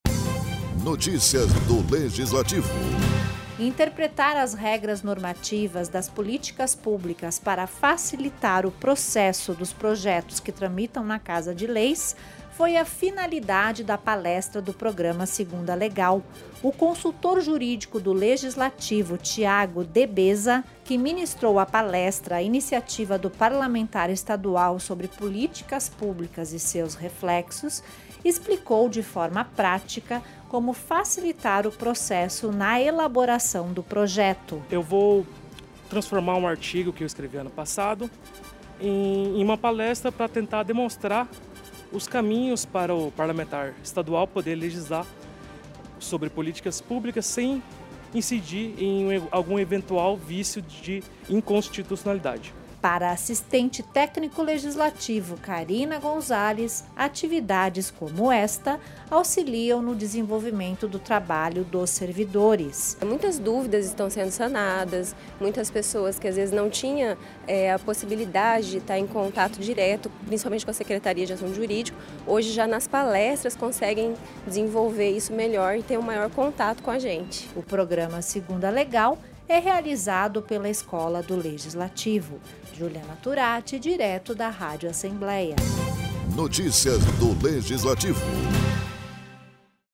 Download Locução e Produção